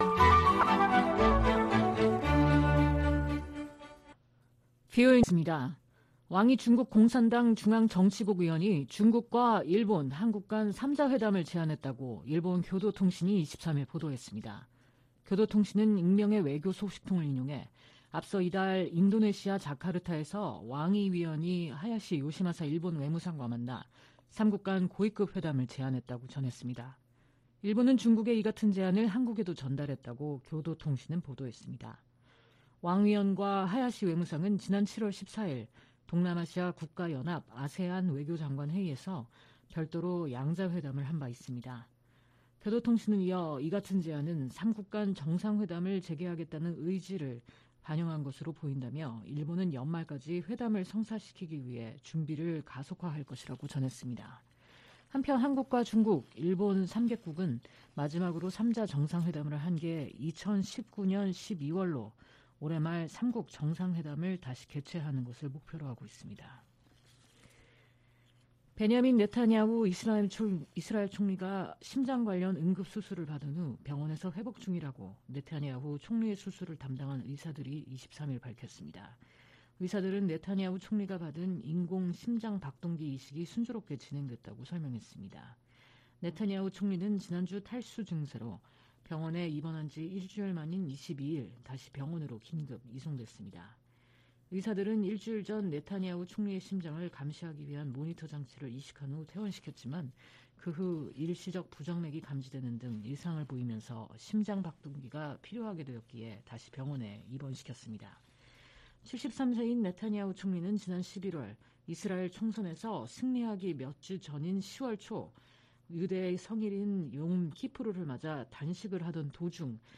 VOA 한국어 방송의 일요일 오후 프로그램 3부입니다. 한반도 시간 오후 10:00 부터 11:00 까지 방송됩니다.